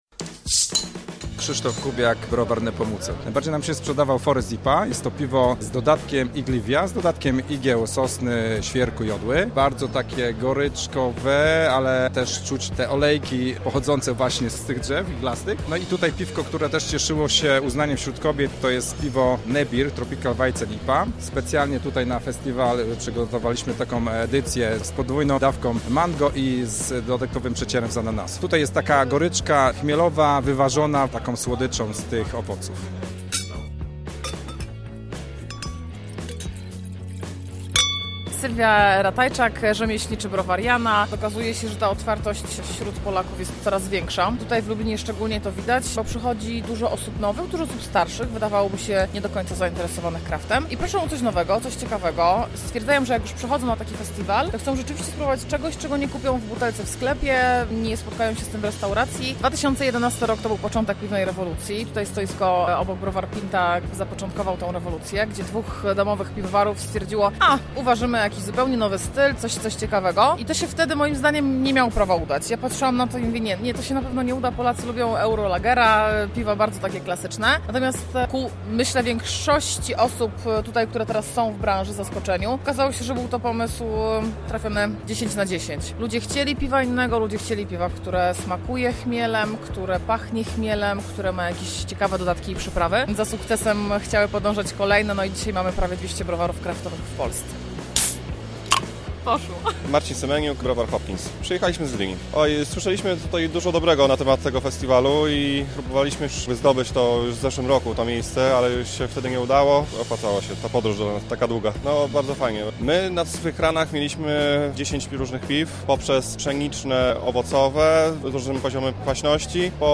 Targi-piwa-relacja.mp3